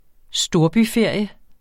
Udtale [ ˈsdoɐ̯by- ]